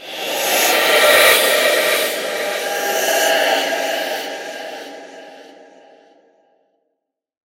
Звуки проклятия
От шепота потусторонних сущностей до зловещих завываний – каждый звук перенесёт вас в мир, где реальность теряет границы.